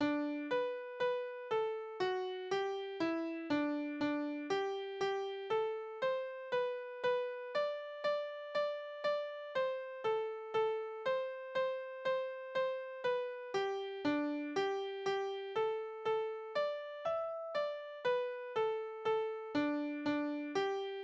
Balladen: Der Knabe und die 2 Mädchen
Tonart: G-Dur
Tonumfang: große None
Besetzung: vokal
Vortragsbezeichnung: langsam.